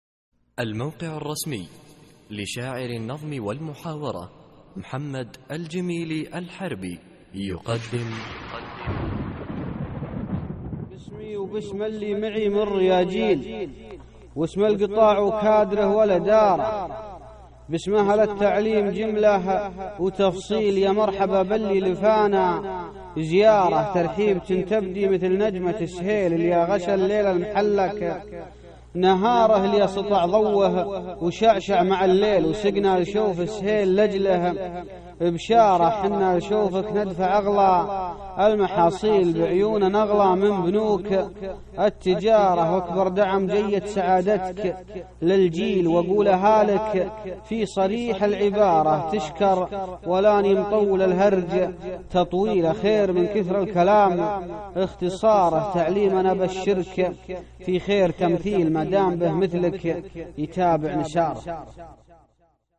القصـائــد الصوتية
اسم القصيدة : نجمة سهيل ~ إلقاء